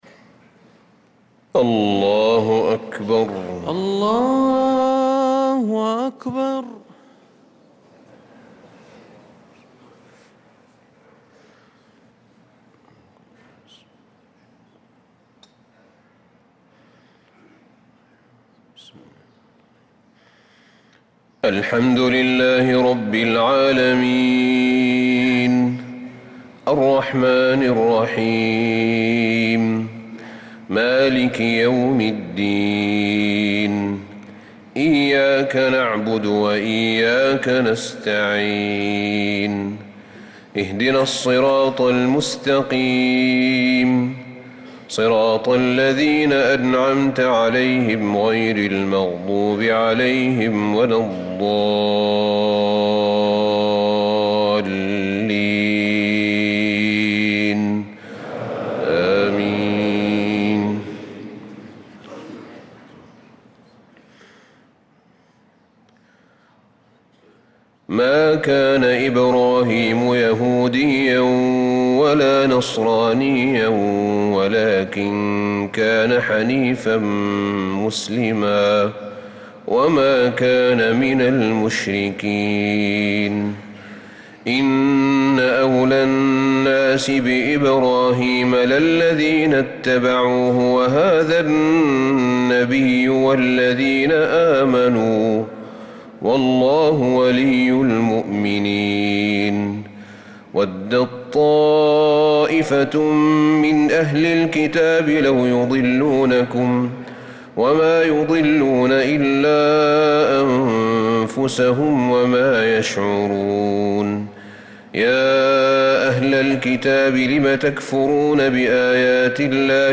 صلاة الفجر للقارئ أحمد بن طالب حميد 18 ذو الحجة 1445 هـ
تِلَاوَات الْحَرَمَيْن .